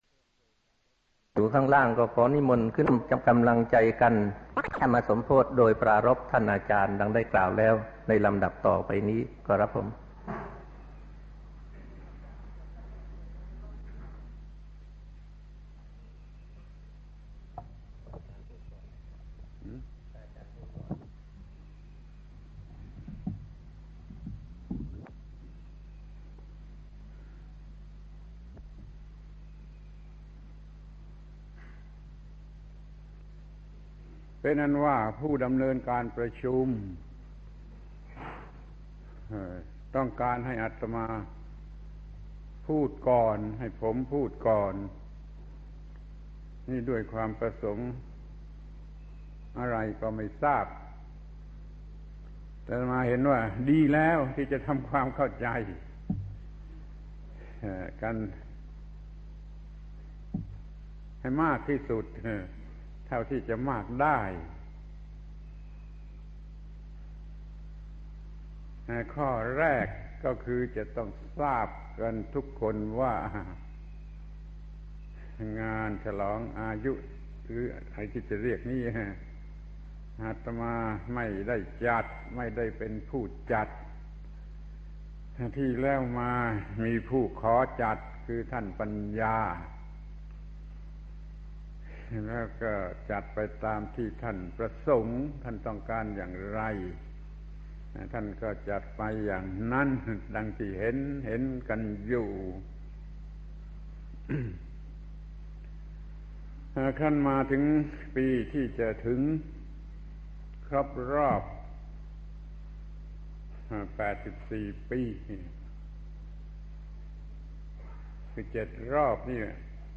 พระธรรมโกศาจารย์ (พุทธทาสภิกขุ) - แสดงธรรมล้ออายุ ปี 2532 ปราศรัยก่อนประชุมเตรียมล้ออายุ ปี 83